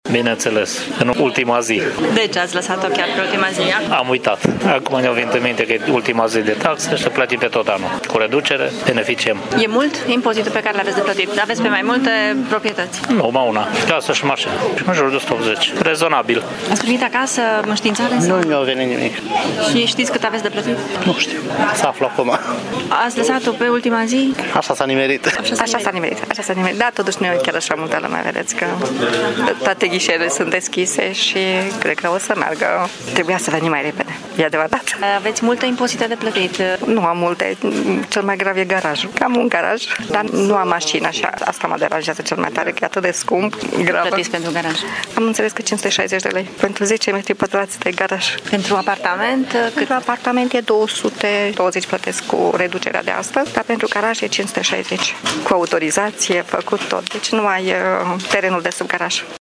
Ei spun că este vina lor că au lăsat plățile pe ultima zi: